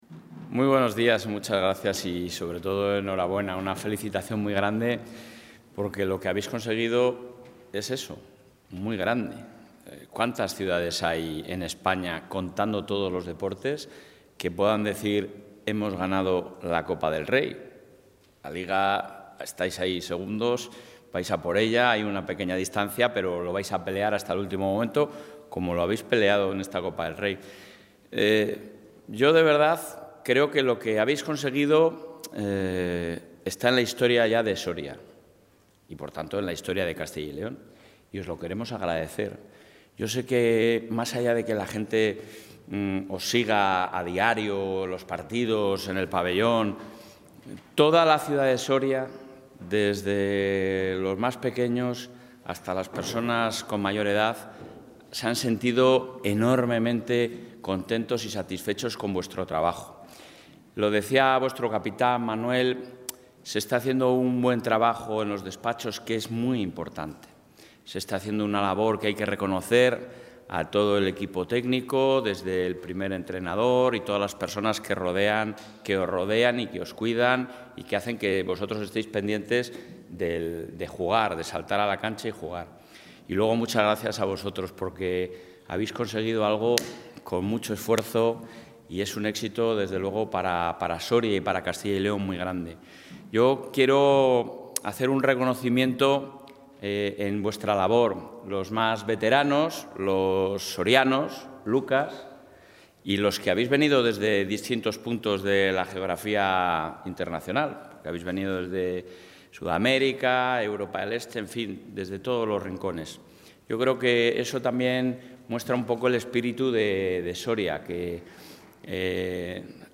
En la recepción celebrada en la Sala de Mapas de la sede de la Presidencia, el presidente de la Junta de Castilla y León, Alfonso...
Intervención del presidente.